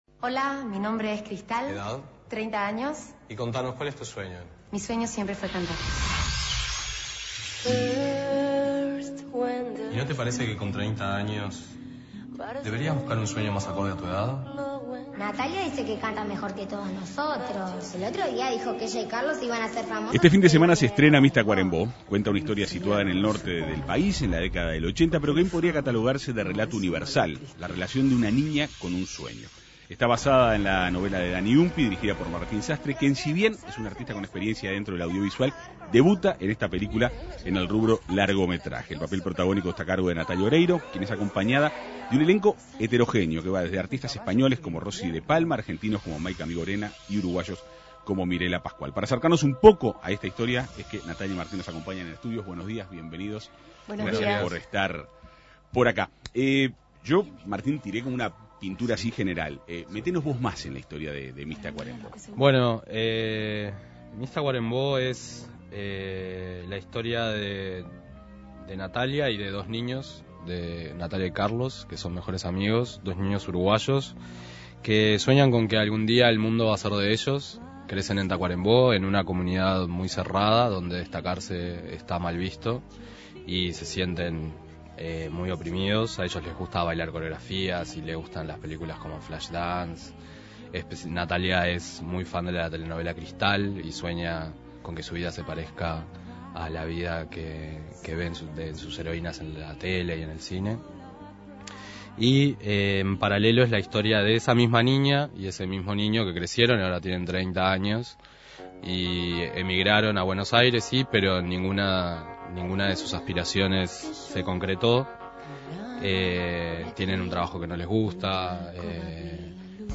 La actriz y el director del filme, Martín Sastre, dialogaron en la Segunda Mañana de En Perspectiva.